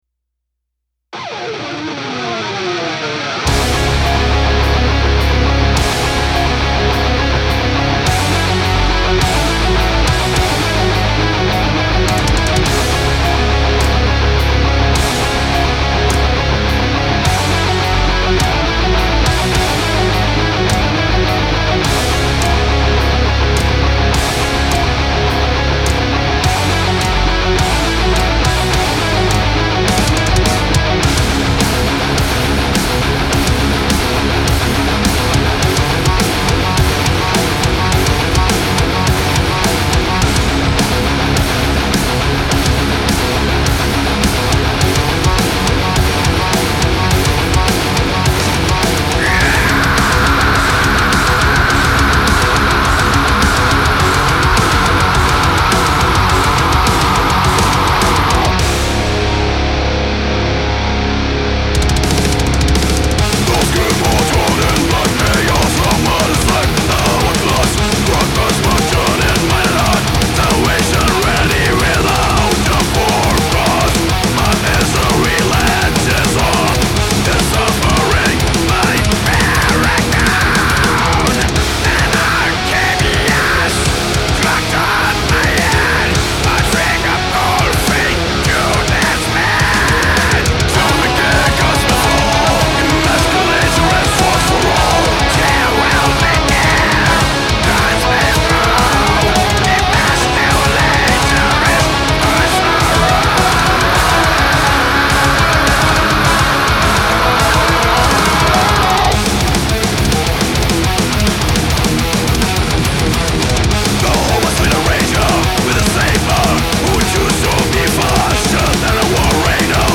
Death/Thrash Metal